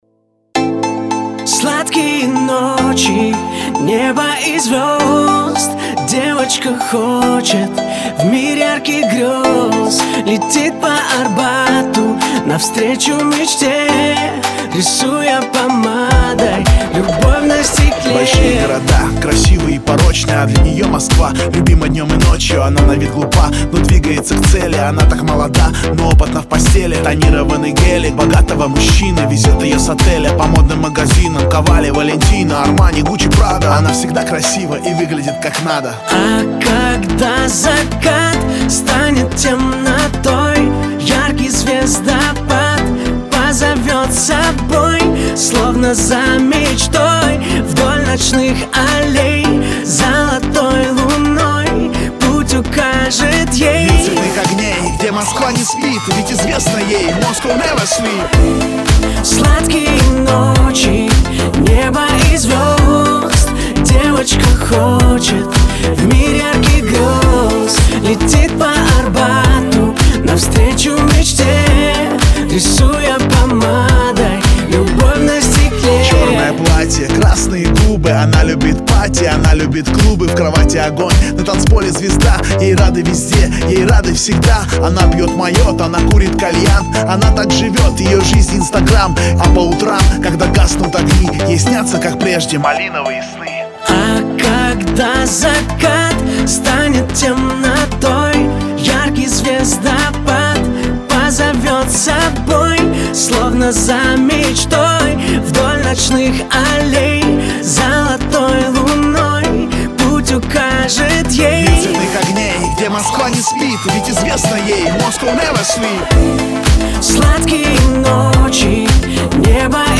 Главная » Файлы » Поп Музыка Категория